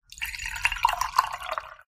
filling_water.ogg